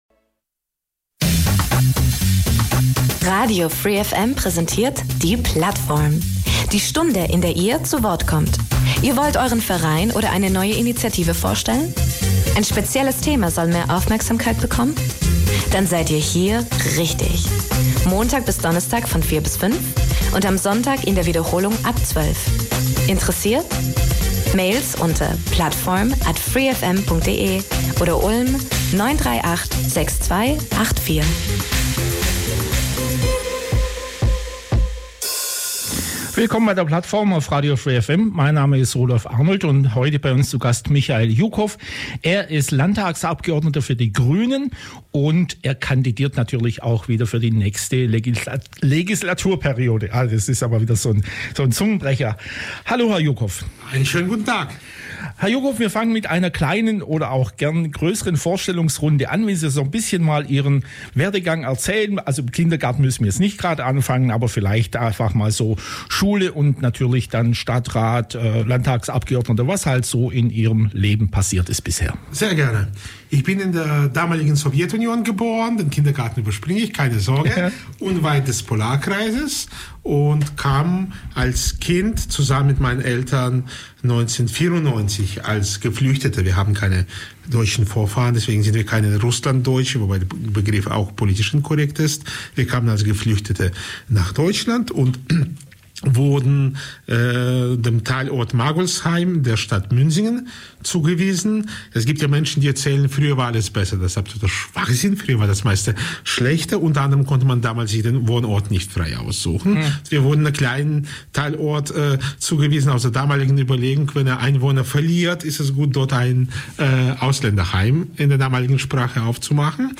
In der heutigen Plattform Sendung geht es um Betrugsdelikte die bekannt sind als "Enkeltrick". Oft geben sich die Täter am Telefon auch als "Polizeibeamte" aus. In den allermeisten Fällen richten sich diese Straftaten gegen ältere Menschen.